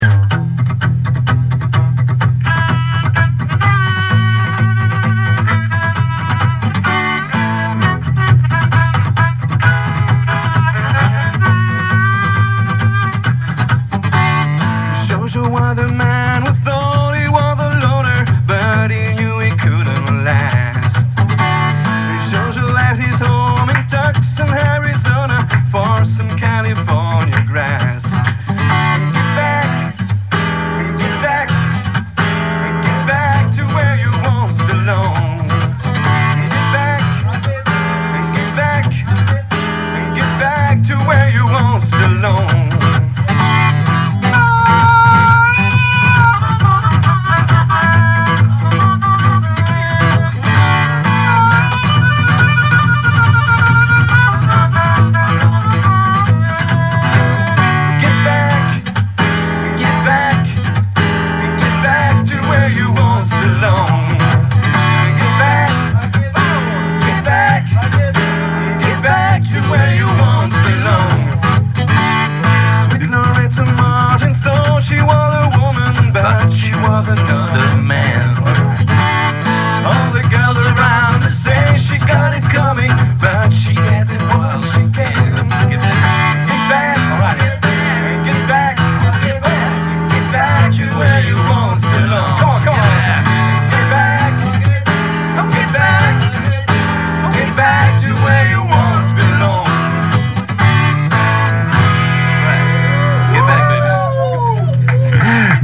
zene nélkül